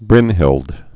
(brĭnhĭld)